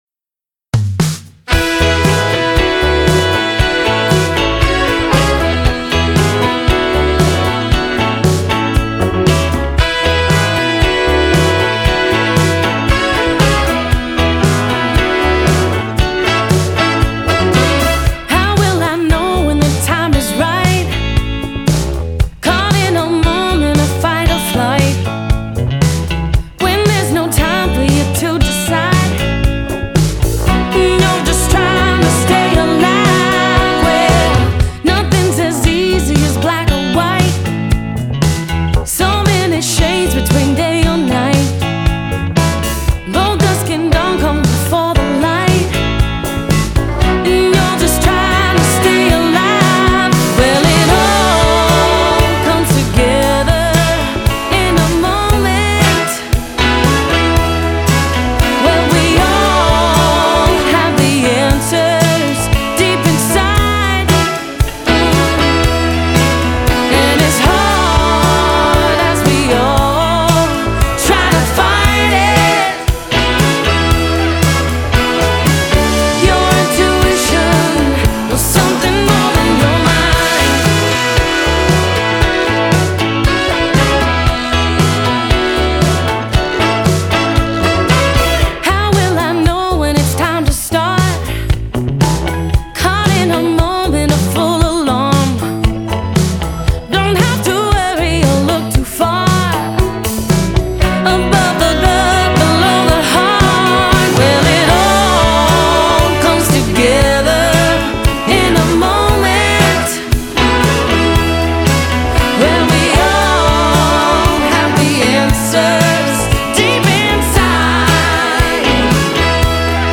on drums
on Organ
on Keys
Bass
Here is the title song….